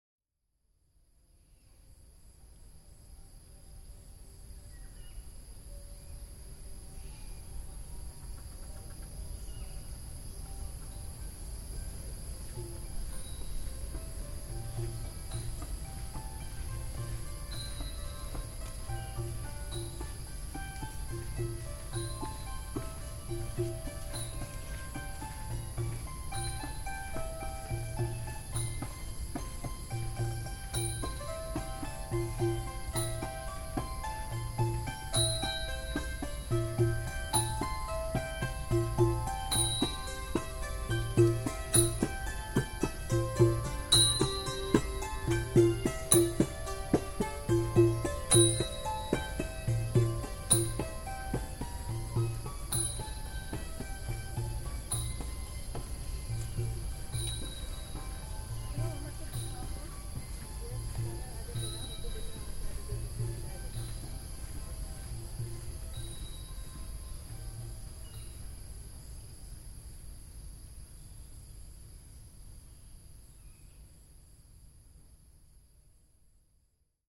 I recorded this beautiful soundscape in the grounds of Angkor Wat. A group of Buddhist monks were playing instruments and praying from within the temple so I began recording and slowly approached the open windows, before walking on past.